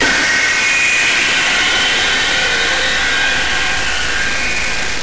Звуки дьявола, черта
Дьявольский звук крика